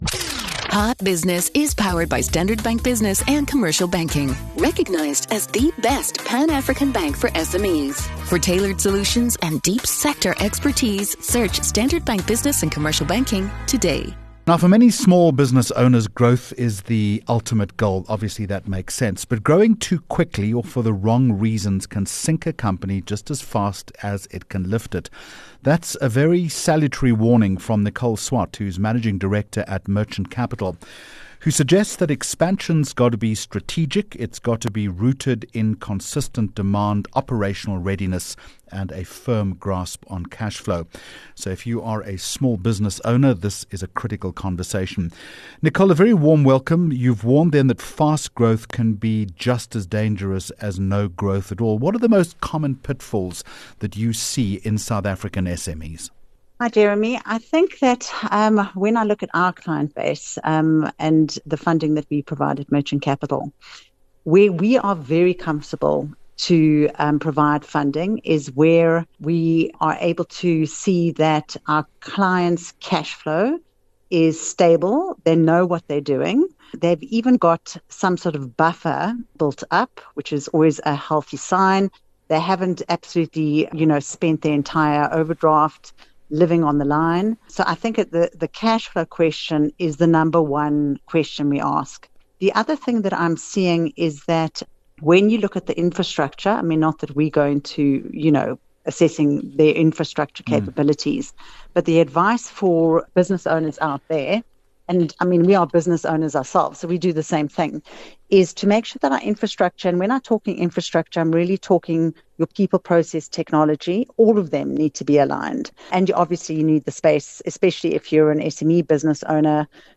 12 Aug Hot Business interview